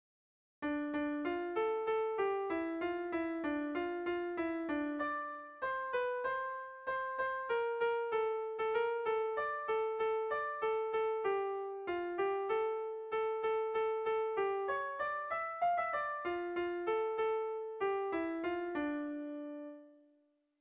Erromantzea
Erritmo interesgarria du.